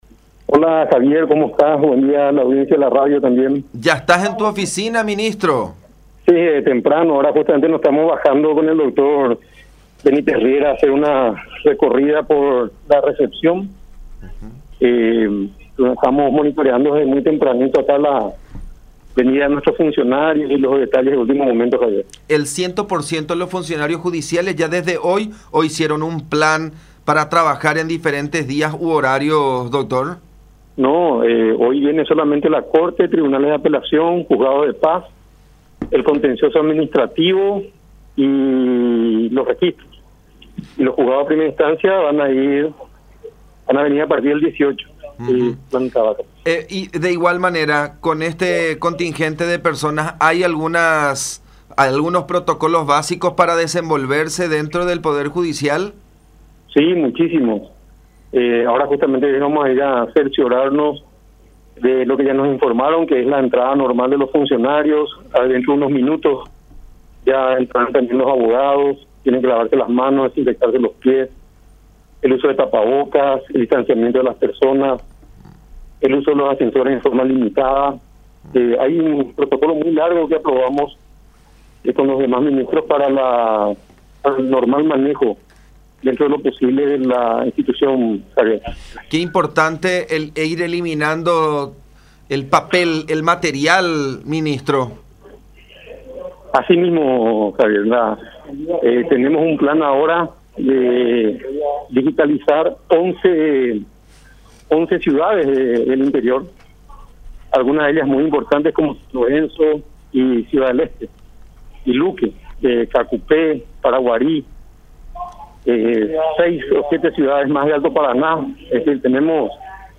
“Desde hoy retomamos las actividades en un cierto porcentaje y en algunas áreas. Estamos controlando y monitoreando todo lo relativo a los sistemas de seguridad sanitaria para el ingreso a la sede judicial de nuestros funcionarios. Hay un protocolo muy largo que aprobamos”, dijo Alberto Martínez Simón, presidente de la Corte Suprema de Justicia (CSJ), en contacto con La Unión.